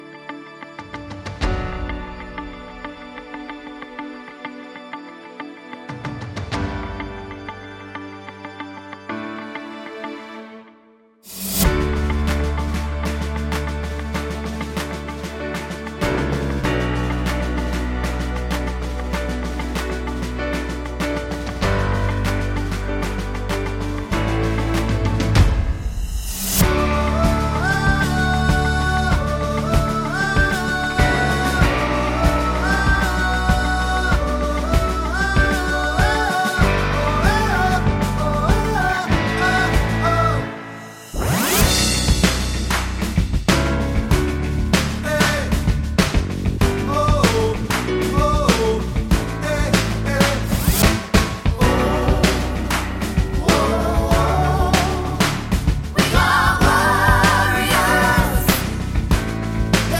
no Backing Vocals Musicals 3:53 Buy £1.50